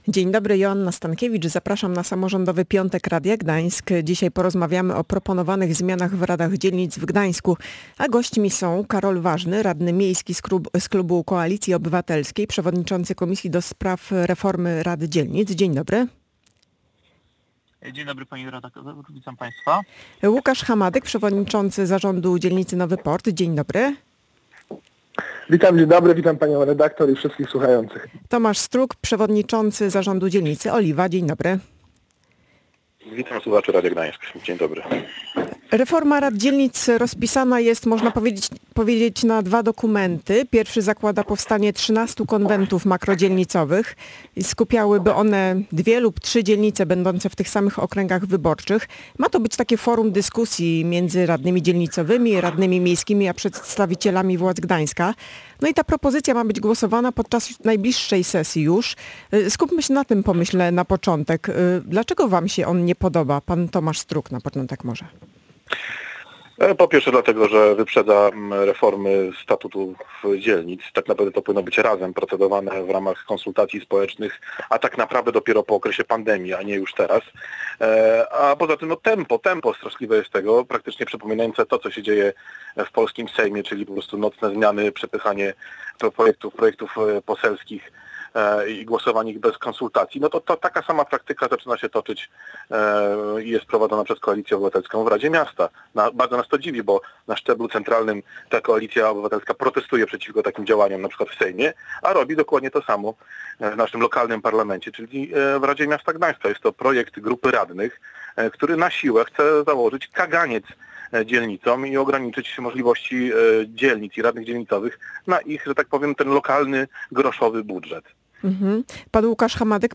O tym w audycji „Samrządowy Piątek”” dyskutowali: Karol Ważny, radny miejski z klubu Koalicji Obywatelskiej, przewodniczący komisji ds. reformy rad dzielnic, Łukasz Hamadyk, przewodniczący zarządu dzielnicy Nowy Port i Tomasz Strug, przewodniczący zarządu dzielnicy Oliwa.